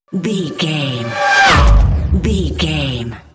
Sci fi vehicle whoosh fast
Sound Effects
Fast
futuristic
whoosh